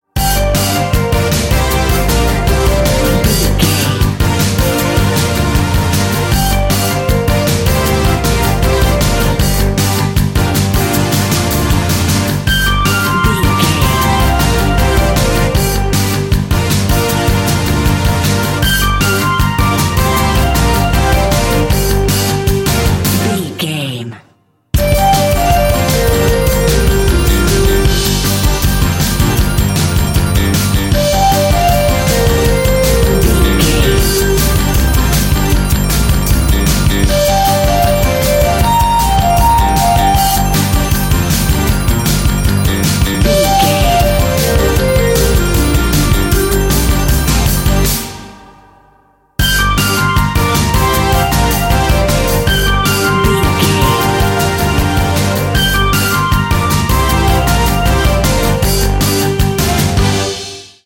Dorian
Fast
driving
energetic
frantic
bass guitar
synthesiser
percussion
electric piano